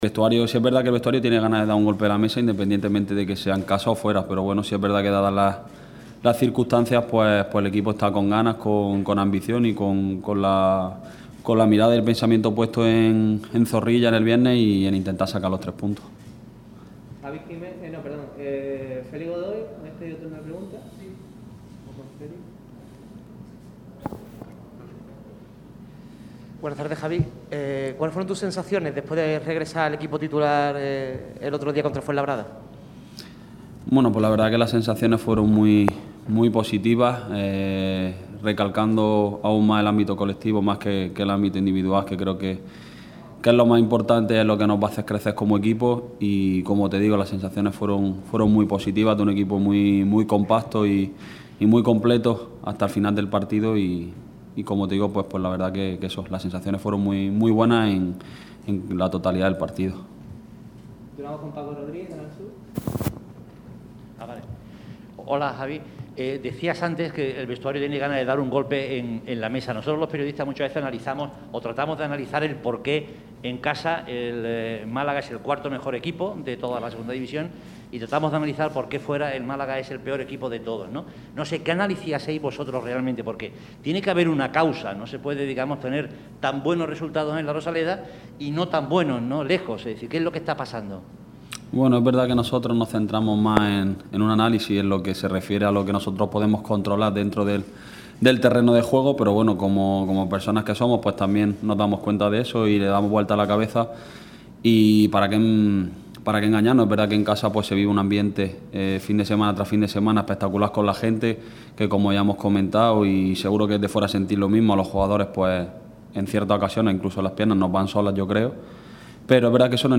El lateral izquierdo del Málaga CF compareció en rueda de prensa antes del Valladolid